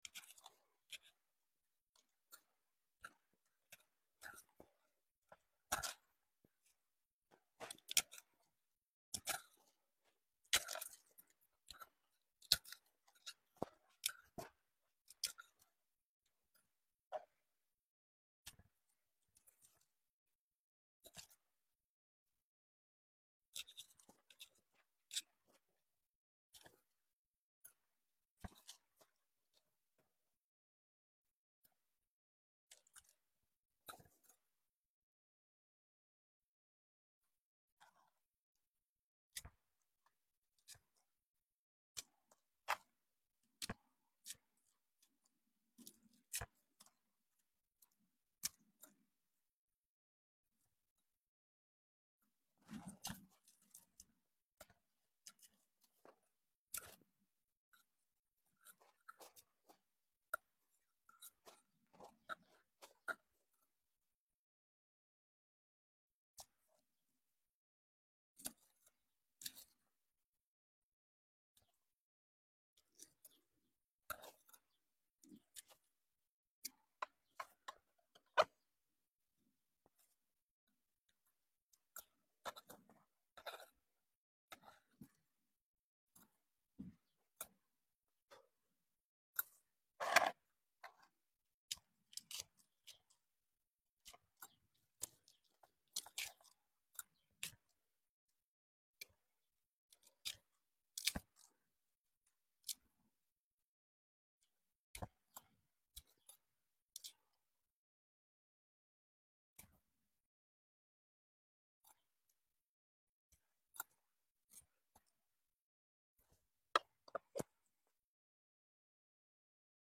Settle in for an extended ASMR session as I peel away layers of dry succulent leaves. Enjoy every satisfying snap and crisp sound for pure relaxation and plant care bliss.